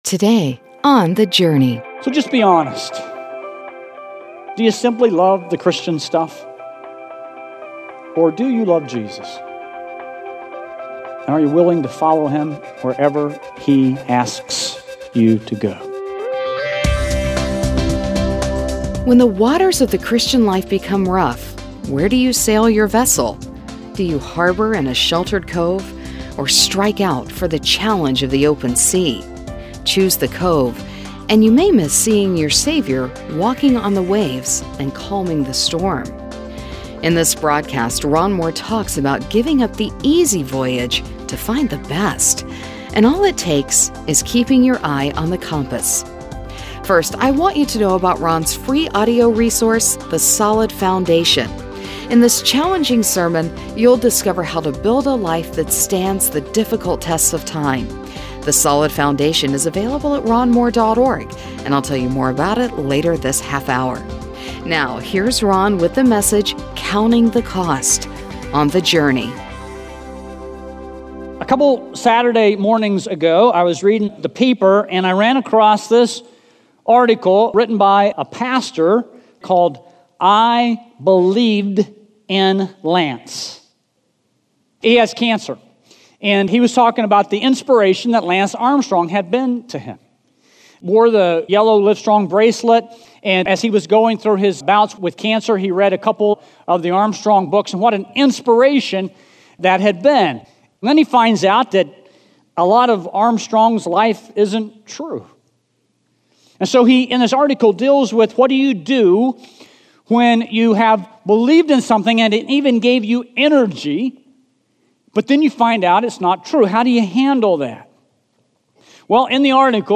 Daily Broadcast